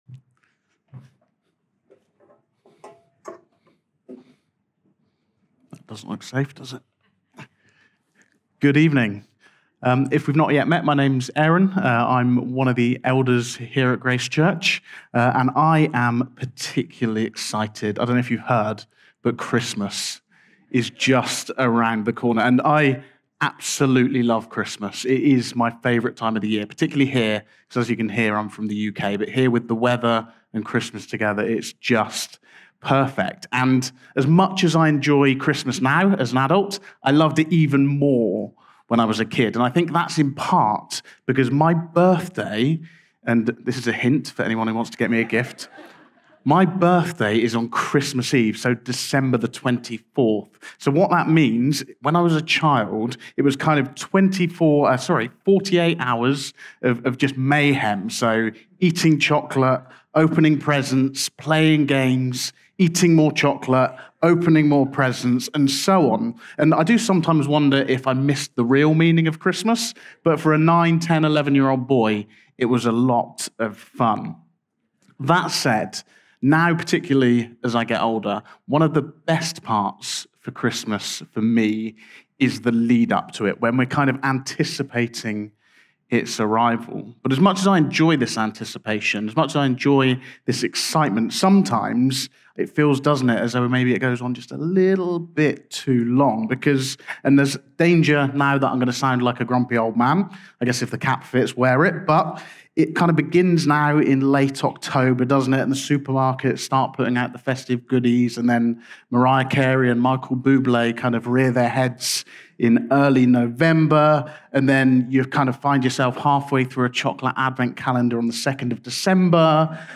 This sermon explores the Old Testament prophecies foretelling the birth of Jesus, highlighting the anticipation and fulfillment of God's promises through the arrival of the Savior. It emphasizes the significance of Jesus' birth as the ultimate realization of divine prophecy and the hope it brings to the world